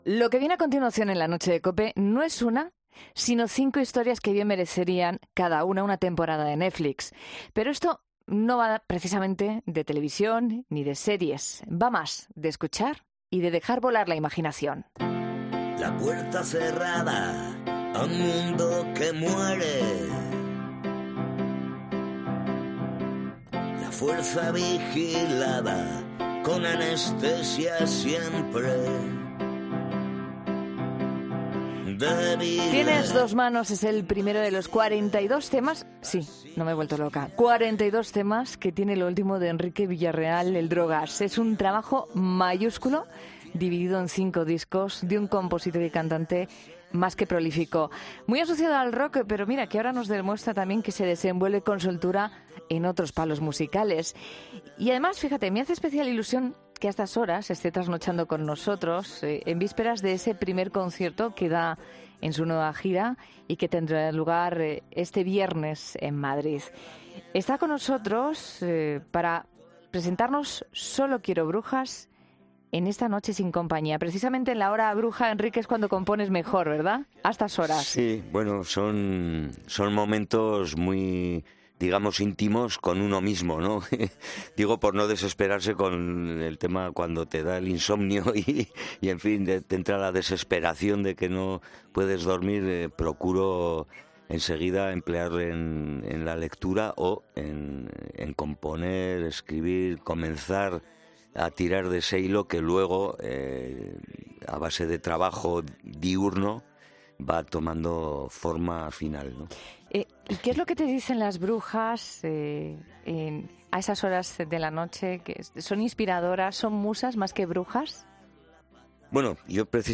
El cantante Enrique Villarreal nos acerca su último trabajo formado por 42 temas diferentes